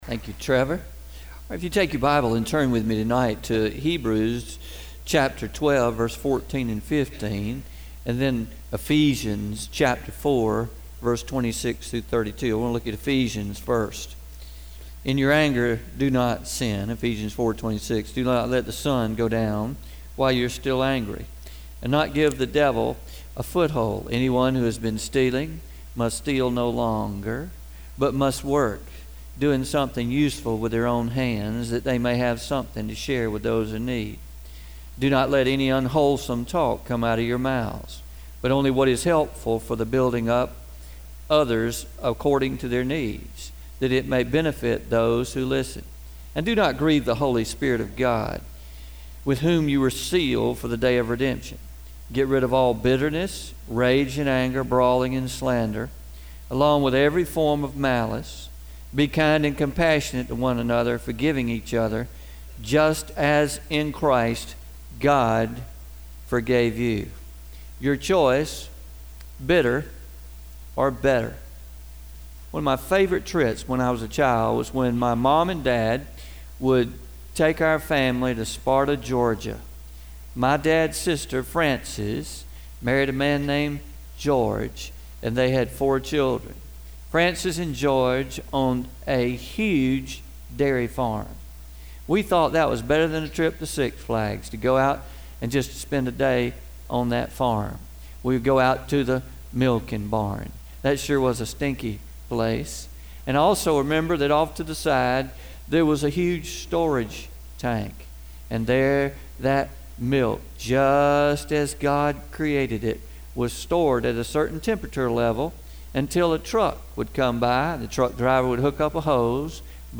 Message from Sunday evening August 20, 2017- Your Choice: Better or Bitter